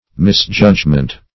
Misjudgment \Mis*judg"ment\, n. [Written also misjudgement.]